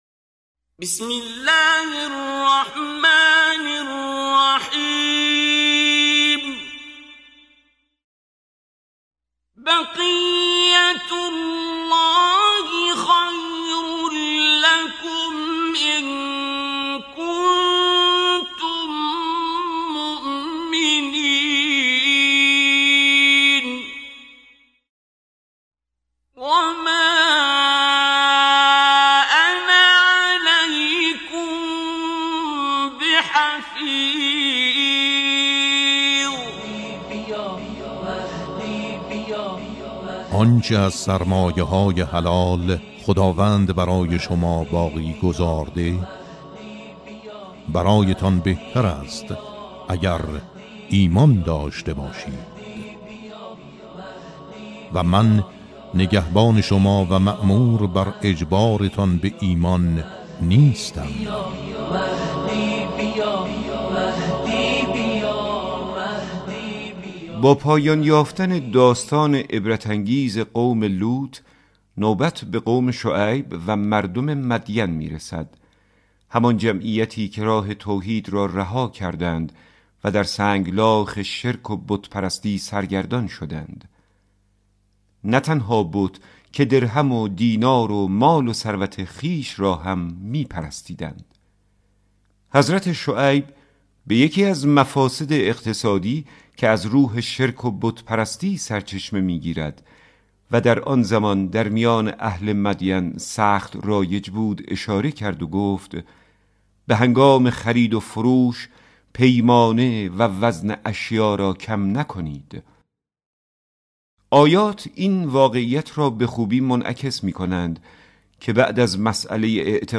به مناسبت سالروز ولادت حضرت ولی‌عصر (عج) برخی از این آیات را در مجموعه «آیه‌های مهدوی» با صدای کریم منصوری، قاری بنام کشور همراه با تفسیر کوتاه این آیه می‌شنوید. در چهارمین قسمت آیه 86 سوره مبارکه هود ارائه می‌شود.